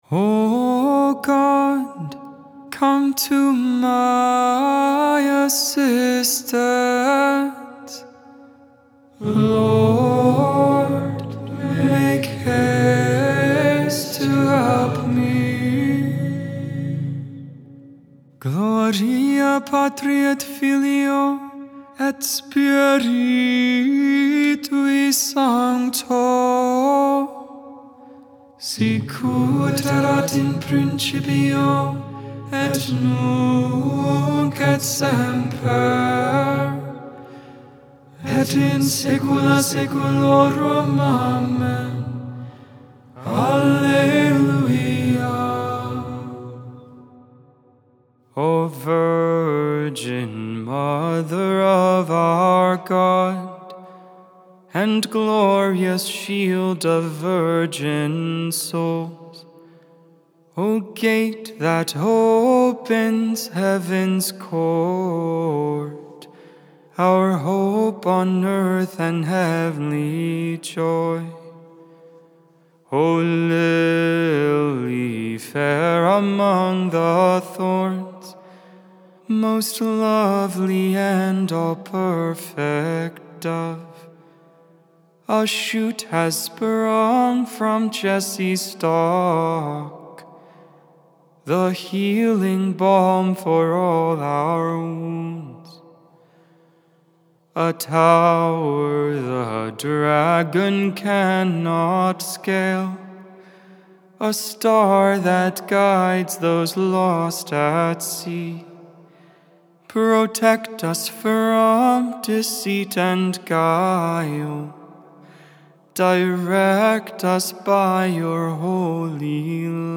The Liturgy of the Hours: Sing the Hours